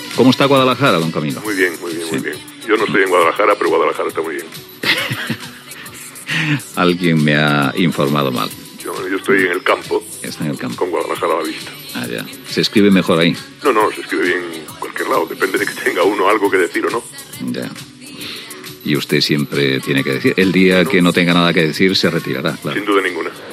Fragment breu d'una entrevista a l'escriptor Camilo José Cela
Info-entreteniment